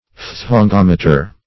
Search Result for " phthongometer" : The Collaborative International Dictionary of English v.0.48: Phthongometer \Phthon*gom"e*ter\, n. [Gr.